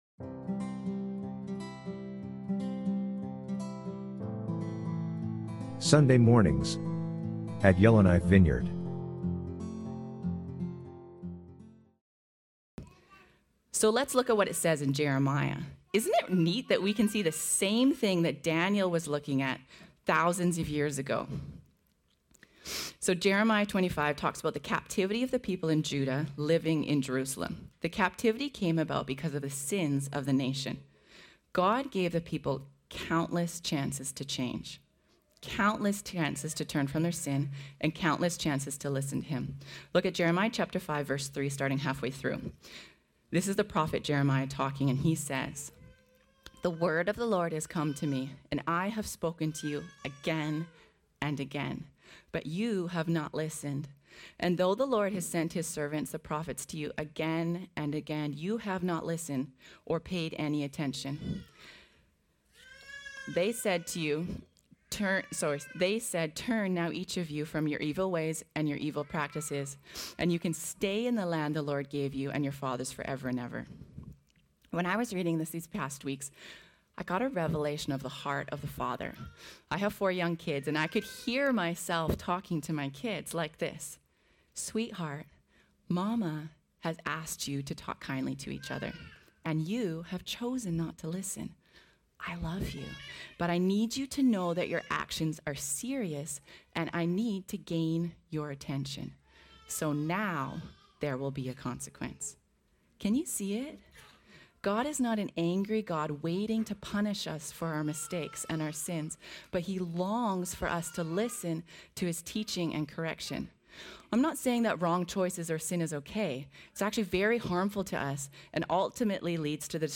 Sermons | Yellowknife Vineyard Christian Fellowship